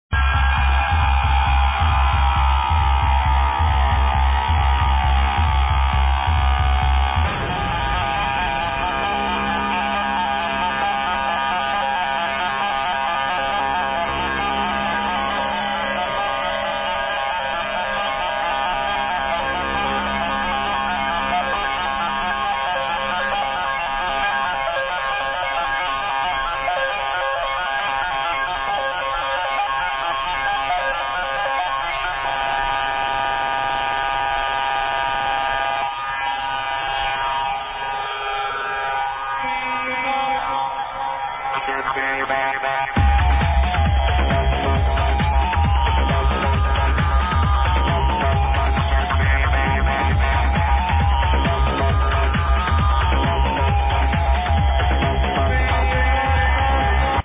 the guitar riff sound